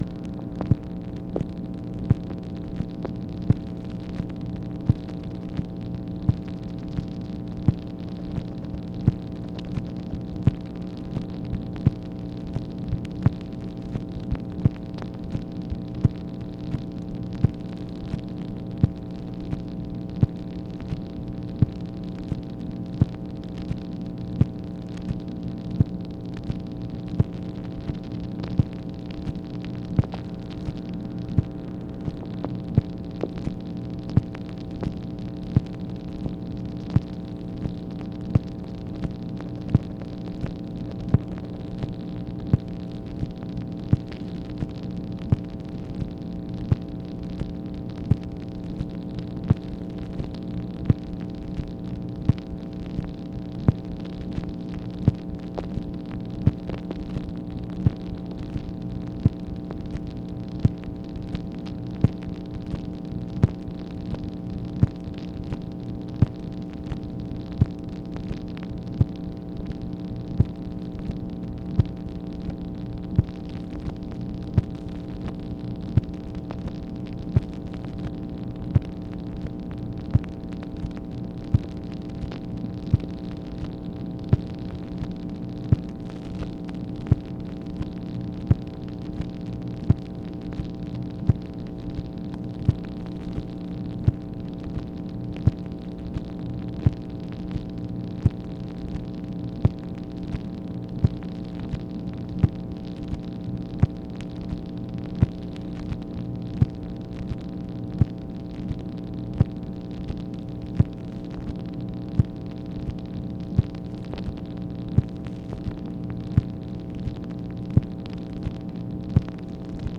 MACHINE NOISE, May 1, 1965